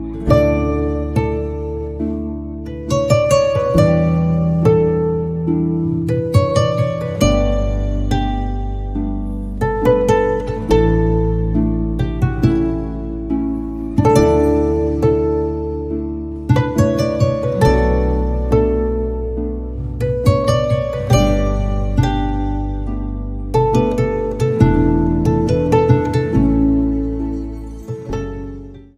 Category: Piano Ringtones